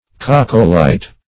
Search Result for " coccolite" : The Collaborative International Dictionary of English v.0.48: Coccolite \Coc"co*lite\, n. [Gr.